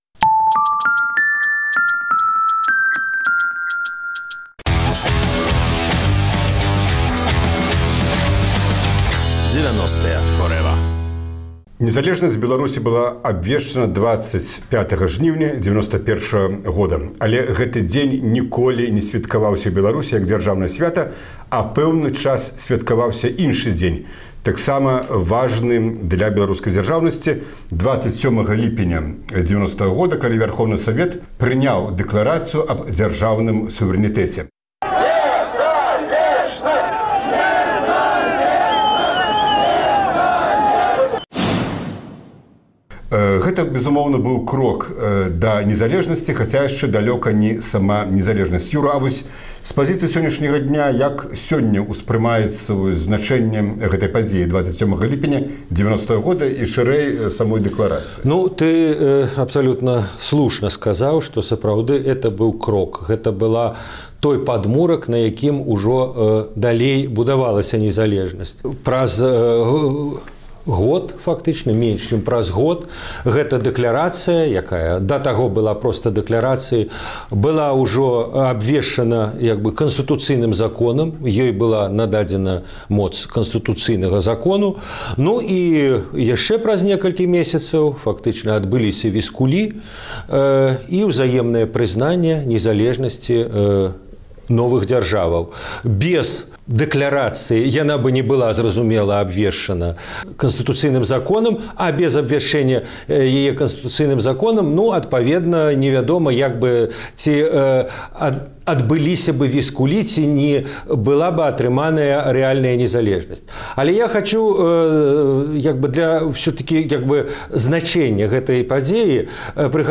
Апошняе інтэрвію з Максімам Лужаніным